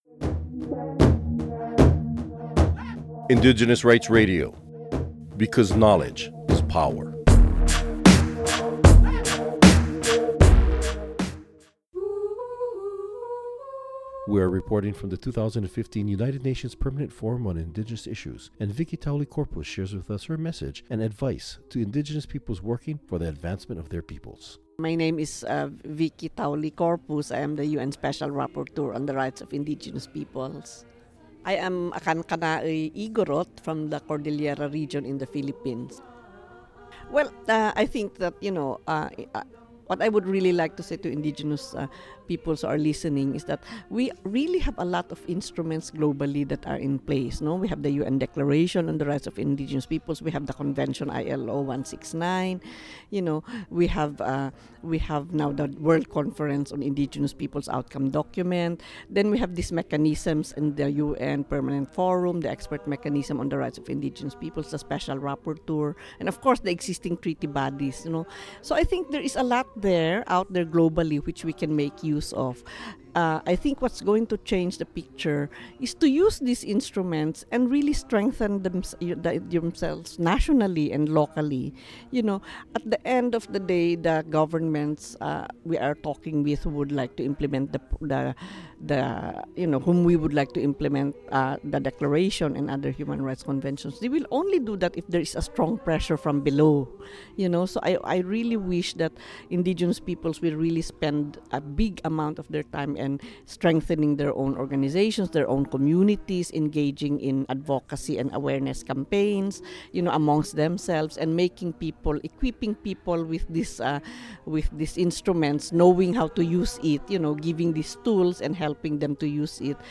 Recording Location: UNPFII 2015
Type: Interview